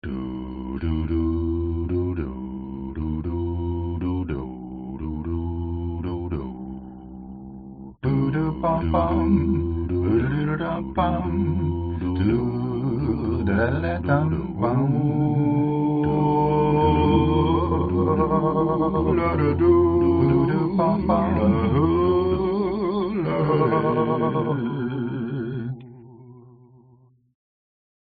节奏是技术性的，这些音源是在Sidechain和Drums部分使用的，在Ableton上制作的。
节奏是技术性的，合成器是用Sidechain与部分鼓声，在Ableton上工作。
female_vocals male_vocals
techno
声道立体声